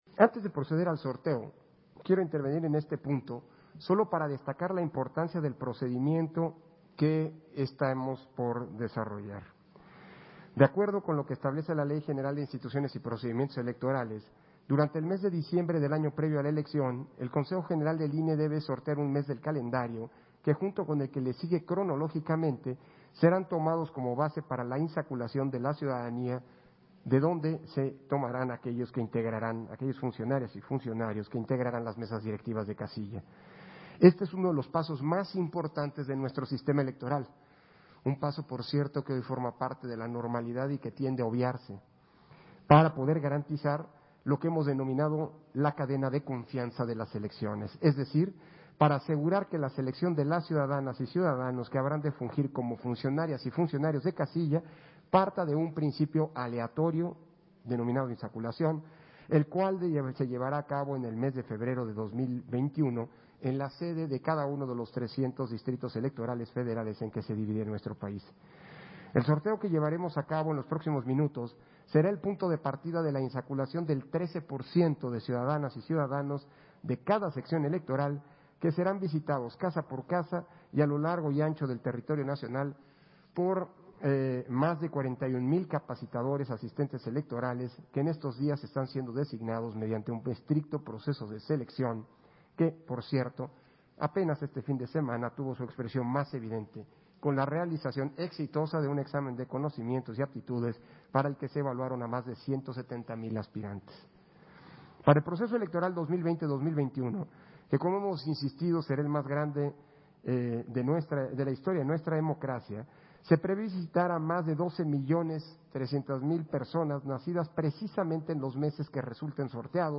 Intervención de Lorenzo Córdova, en el punto en el que realiza el sorteo del mes, junto con el que sigue en orden, como base a la insaculación de las y los integrantes de las Mesas Directivas de Casilla en las Elecciones 2021